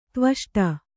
♪ tvaṣṭa